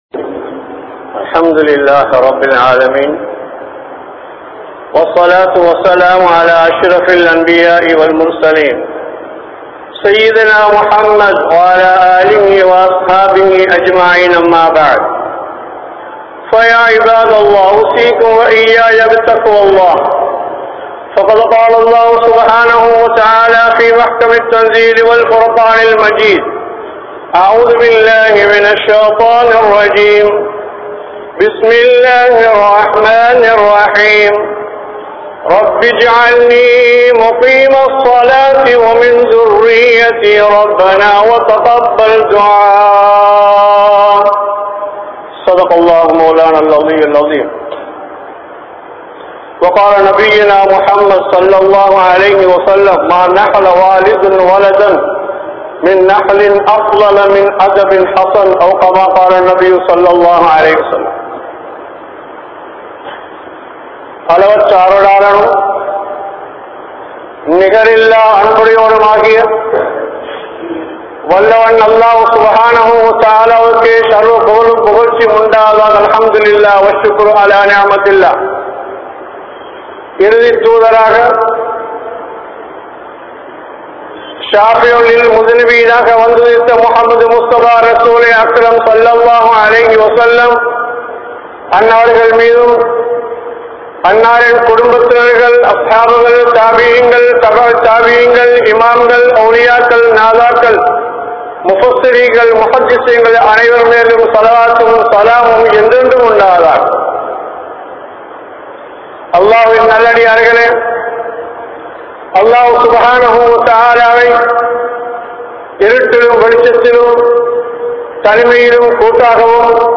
Pettroarhalidam Poik Kaaranam Koorum Vaalifarhal (பெற்றோர்களிடம் பொய்க் காரணம் கூறும் வாலிபர்கள்) | Audio Bayans | All Ceylon Muslim Youth Community | Addalaichenai
Muhiyaddeen Grand Jumua Masjith